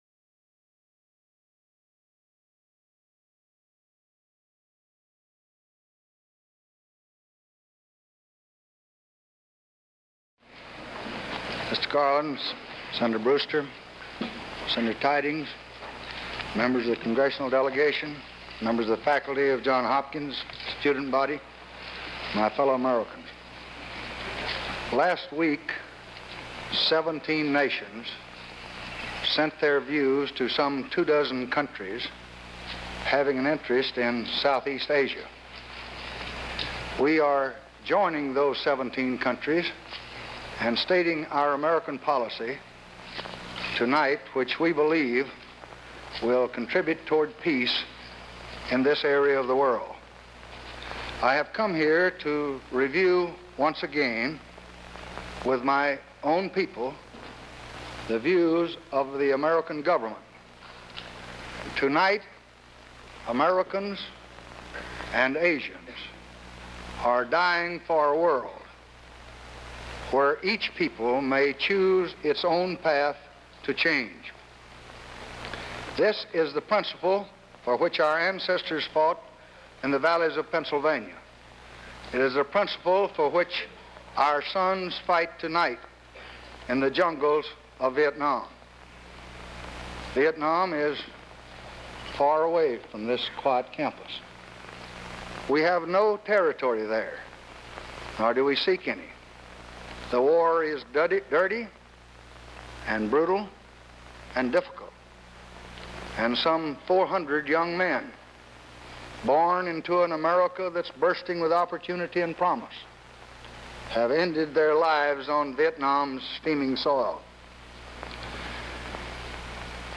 April 7, 1965: Address at Johns Hopkins University
Presidential Speeches | Lyndon B. Johnson Presidency